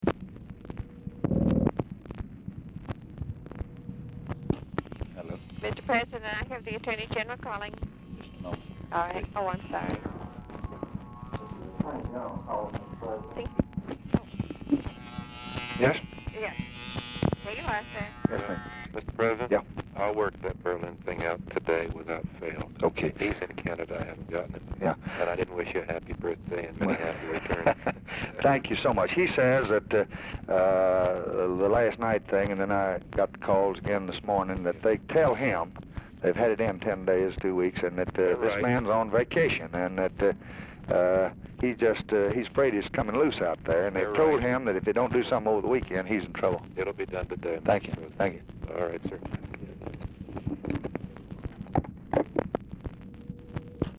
UNIDENTIFIED MALE (MARVIN WATSON?) ANSWERS TELEPHONE, THEN GIVES CALL TO LBJ
Format Dictation belt
Location Of Speaker 1 Mansion, White House, Washington, DC
Specific Item Type Telephone conversation